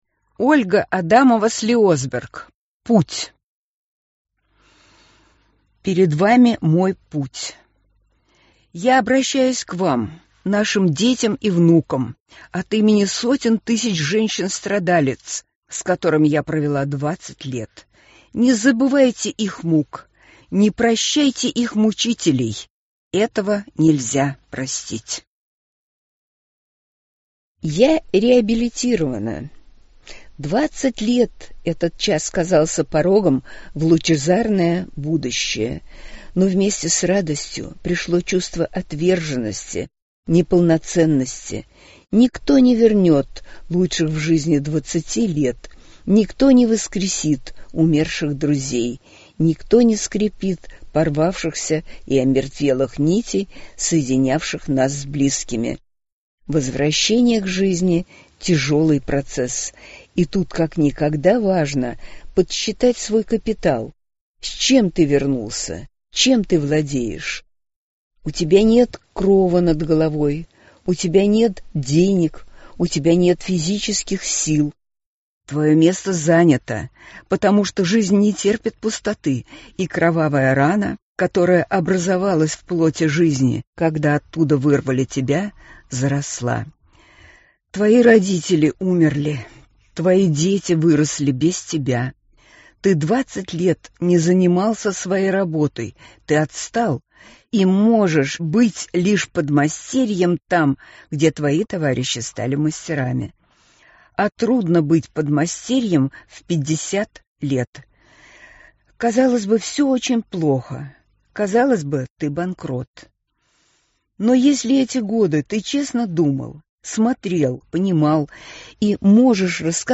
Аудиокнига Путь | Библиотека аудиокниг